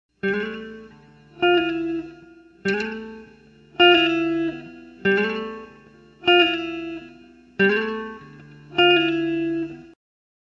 The first slide is a slide on the 4th string from the 5th fret up to the 7th fret.
The second slide is a slide on the 2nd string from the 6th fret down to the 5th fret.
The two slides notated above sound like this:
slide.wav